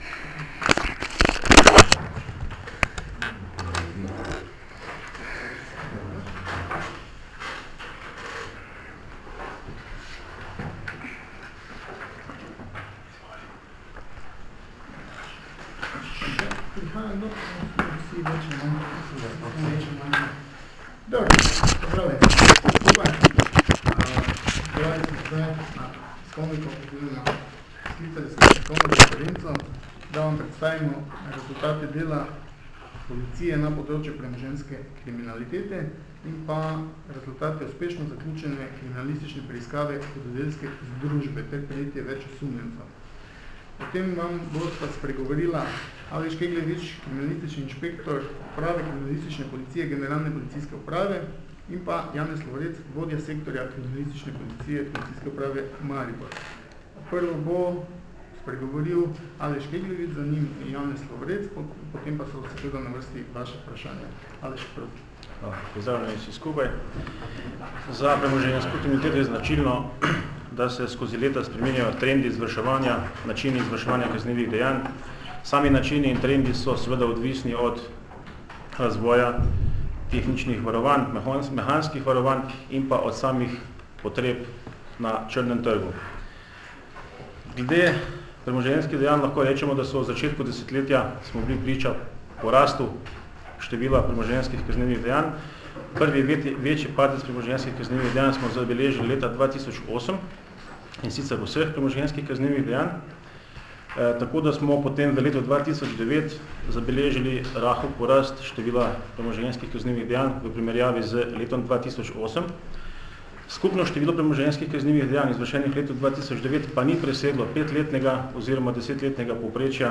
Policija - Razkrili hudodelsko združbo, ki je vlamljala v bencinske servise in trgovine - informacija z novinarske konference